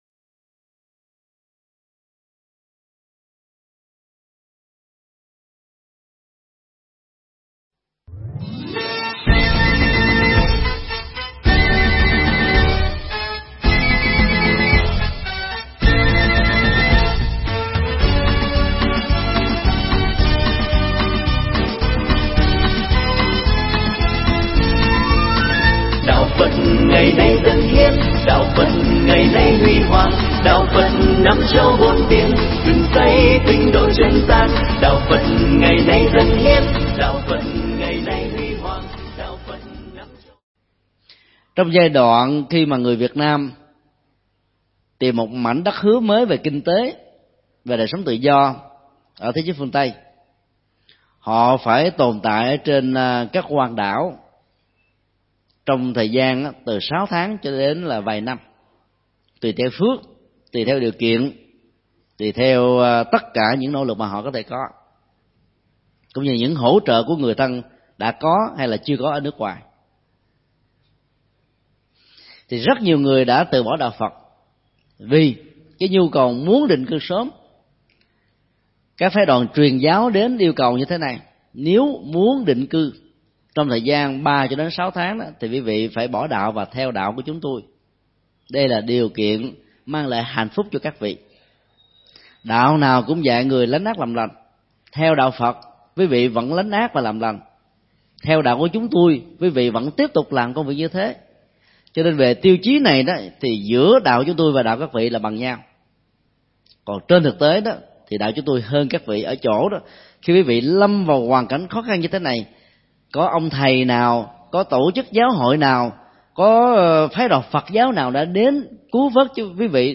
Tải mp3 Bài giảng Kinh Trung Bộ 78 (Kinh Samanamandika) – Điều thiện tối thượng do thầy Thích Nhật Từ Giảng tại Chùa Xá Lợi, ngày 30 tháng 09 năm 2007.